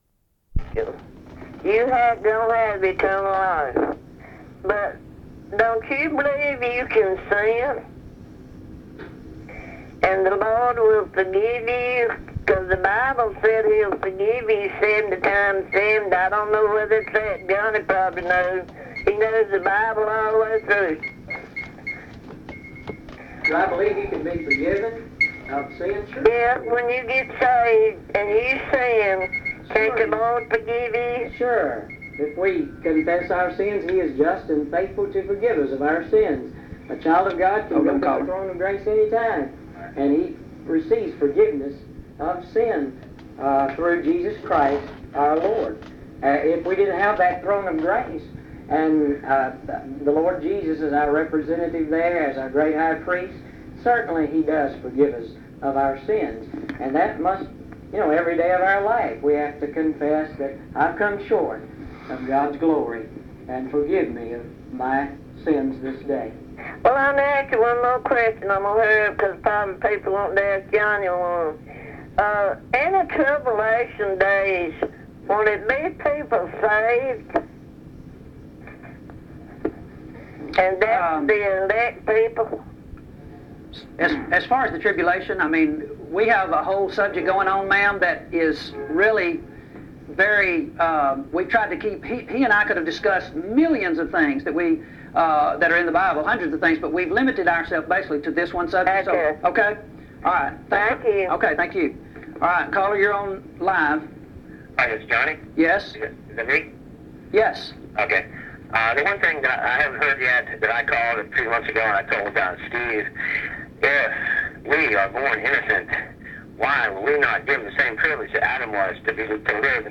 Primitive Baptists